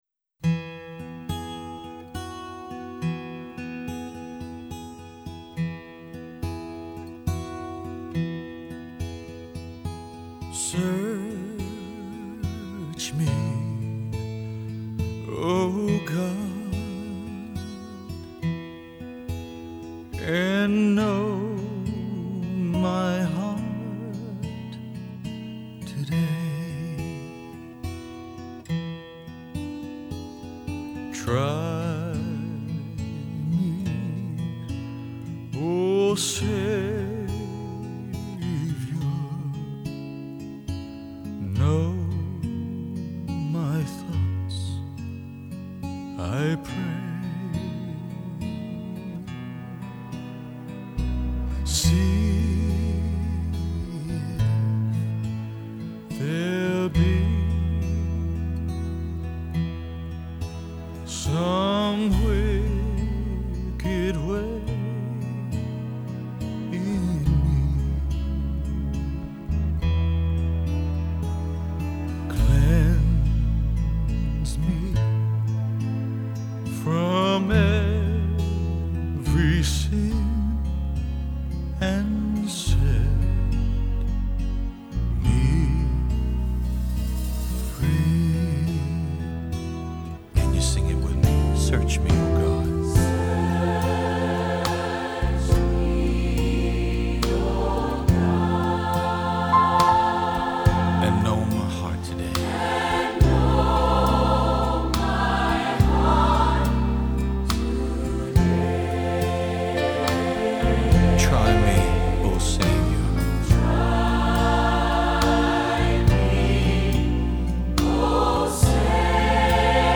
I woke up this morning with this hymn playing through my mind.